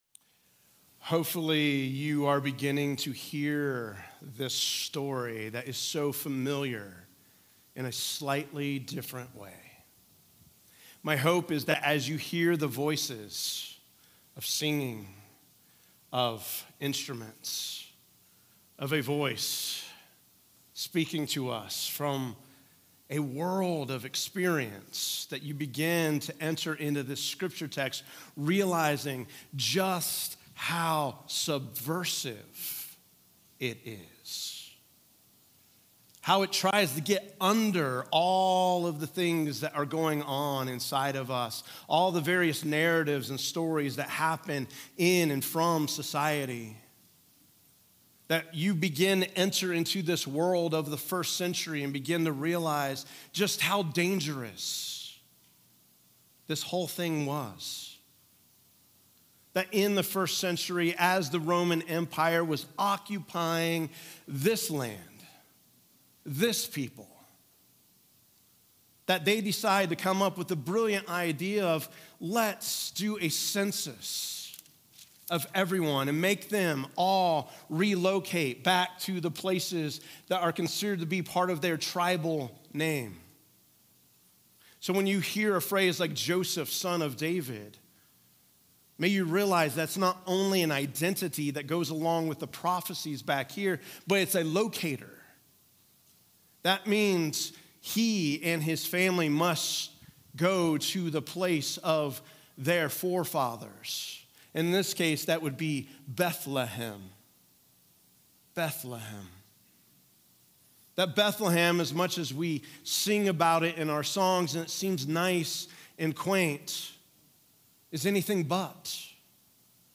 Sermons | Grace Presbyterian Church